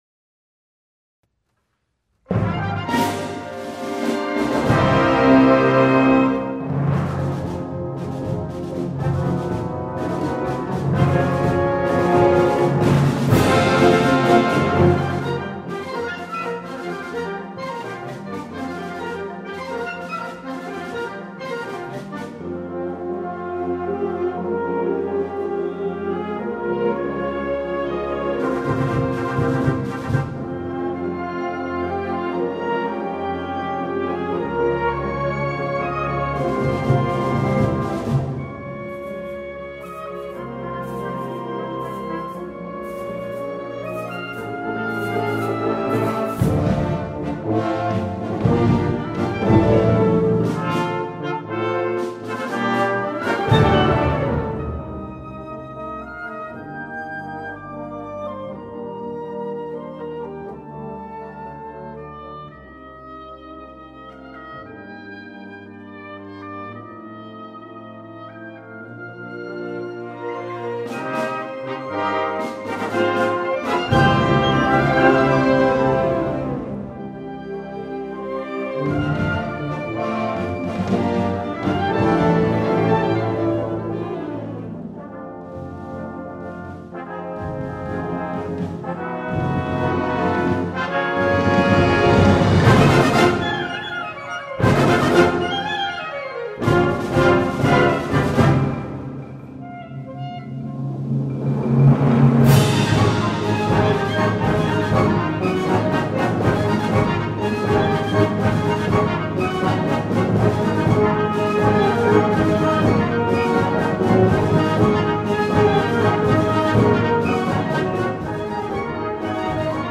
Gattung: Konzertwerk in 5 Sätzen
Besetzung: Blasorchester